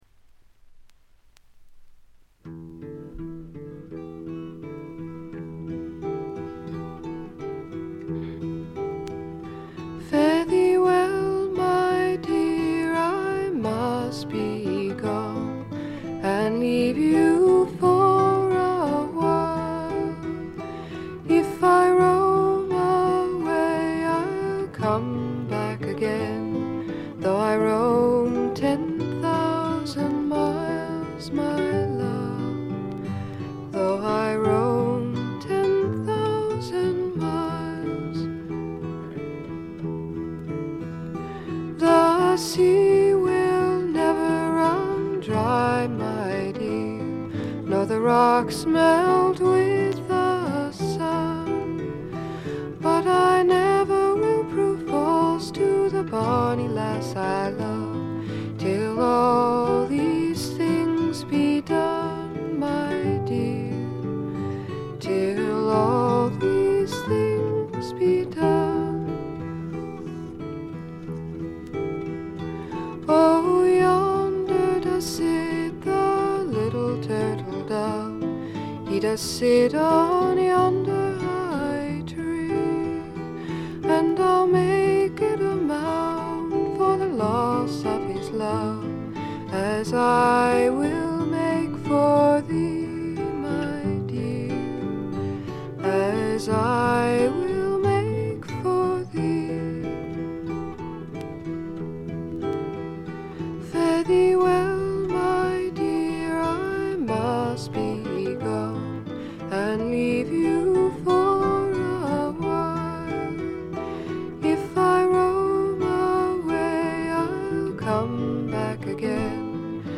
ちょっとハスキーな美声ではかなげに歌われる宝石のような歌の数々。
試聴曲は現品からの取り込み音源です。
guitar
flute
dobro guitar